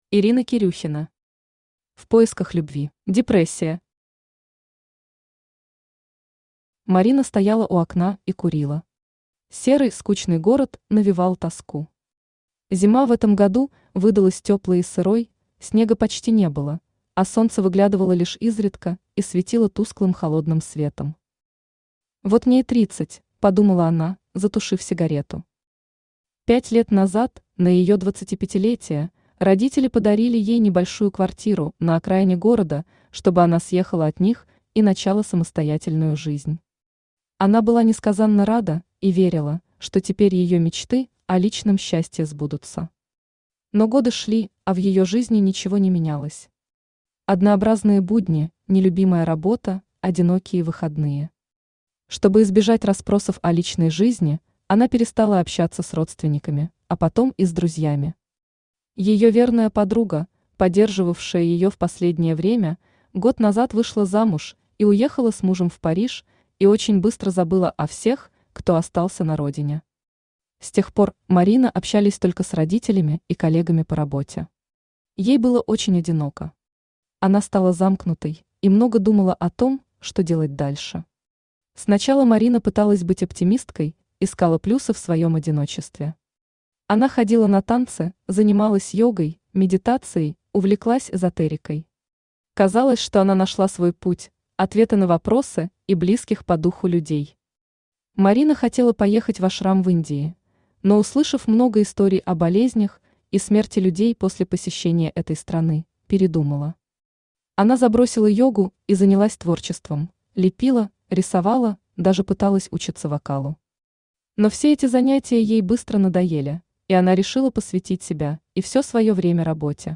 Аудиокнига В поисках любви…
Автор Ирина Кирюхина Читает аудиокнигу Авточтец ЛитРес.